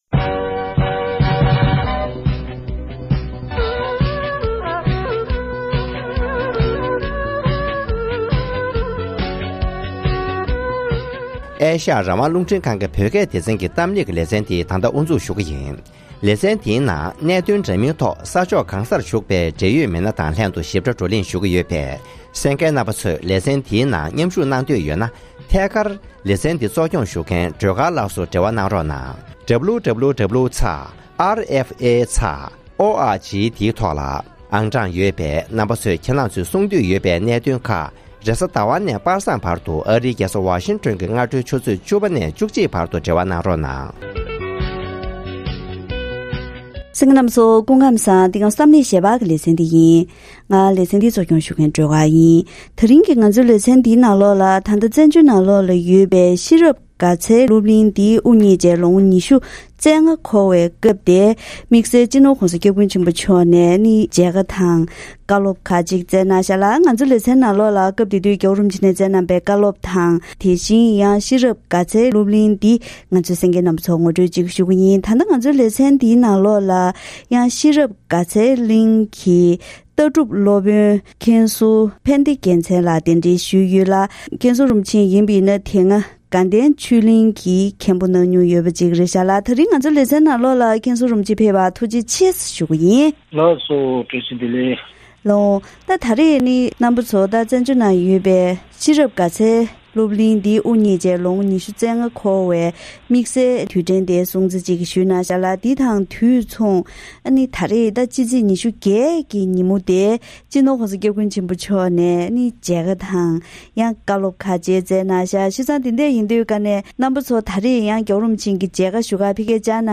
༧གོང་ས་མཆོག་ནས་ཚེས་༢༨ཉིན་ཤེས་རབ་དགའ་ཚལ་སློབ་གླིང་གི་དགེ་སློབ་ལ་མཇལ་ཁ་གནང་བ།